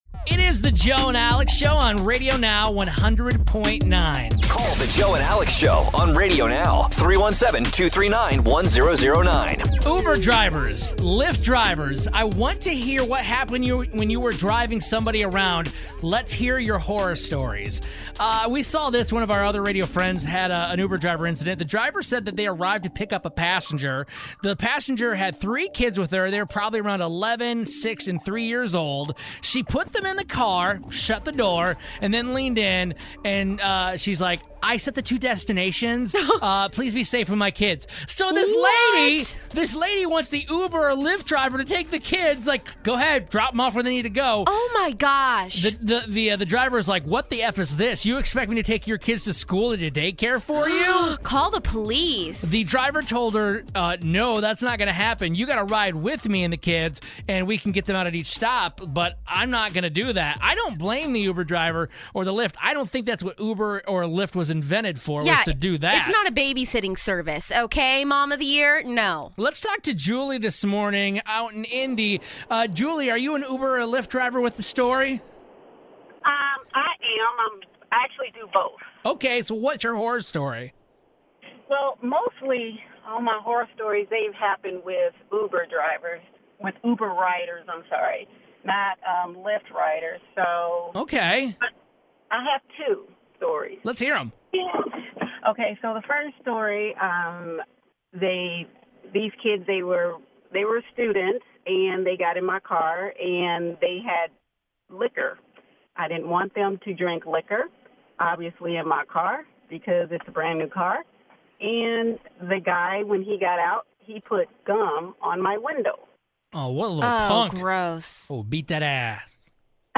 We ask uber/lyft drivers to call in and tell us their worst stories from driving people around.